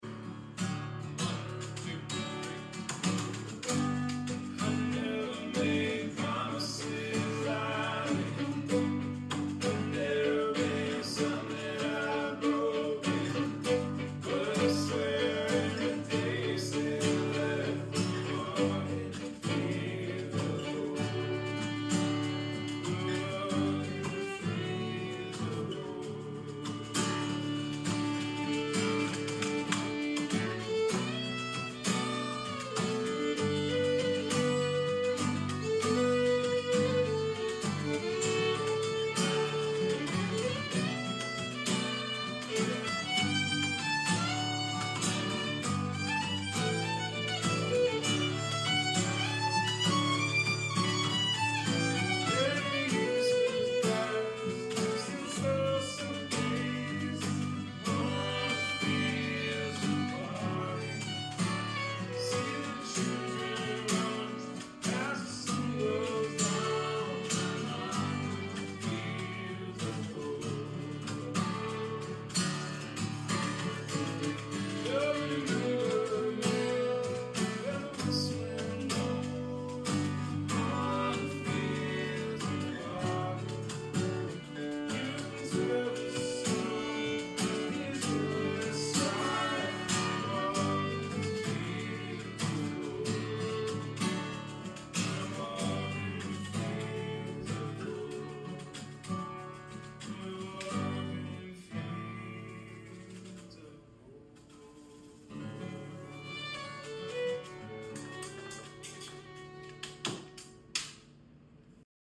fiddle player
singing harmonies together